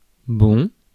Ääntäminen
IPA: /bɔ̃/